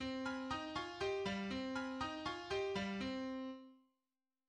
The five-note Deutscher Michel theme, accompanied by tremolo figures in the upper strings, is a reminiscence of the Credo of the Mass in E minor: